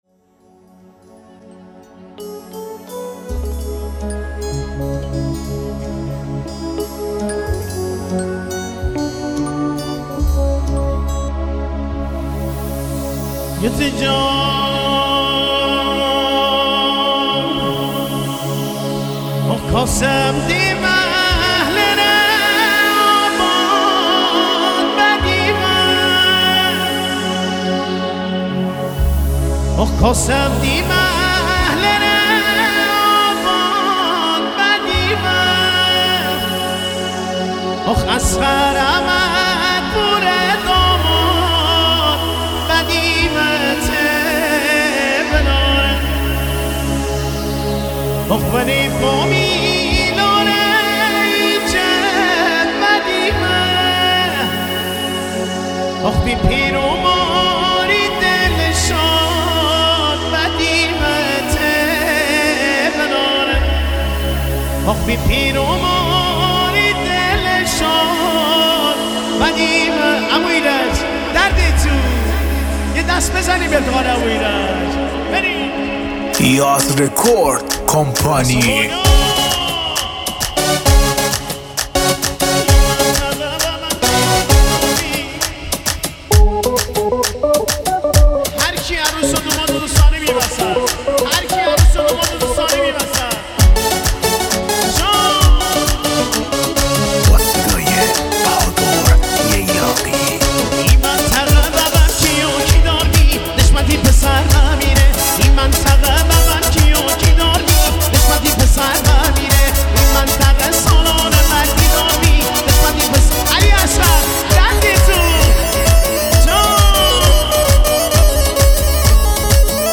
جشنی
با سبک جشنی مازندرانی